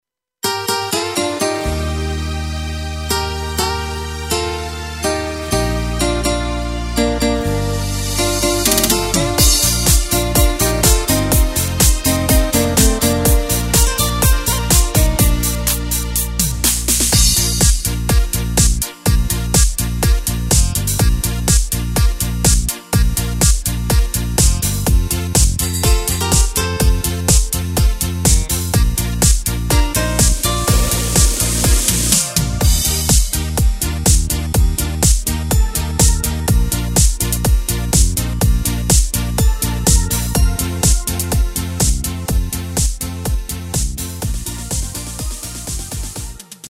Takt:          4/4
Tempo:         124.00
Tonart:            Bb
Discofox aus dem Jahr 2022!
Playback mp3 Demo